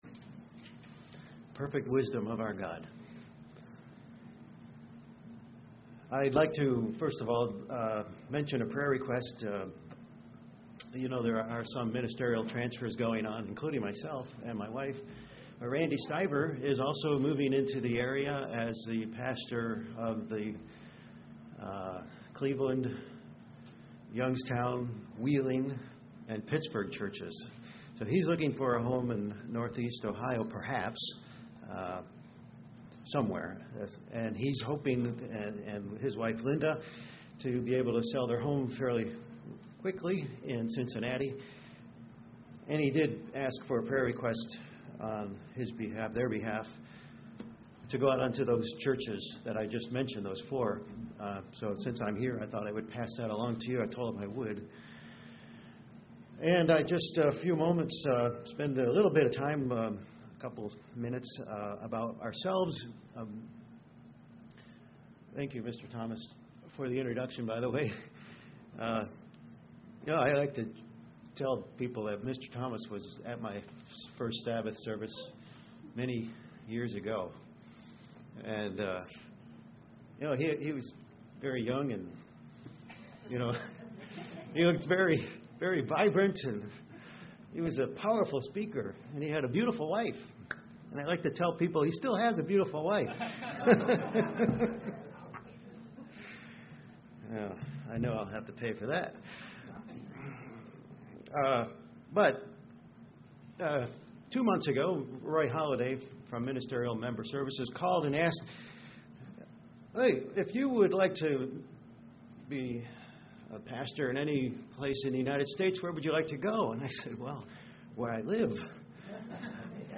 Given in Cleveland, OH
UCG Sermon Studying the bible?